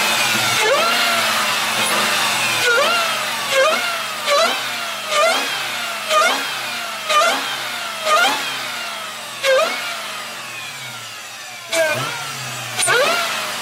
Whine Hellcat Téléchargement d'Effet Sonore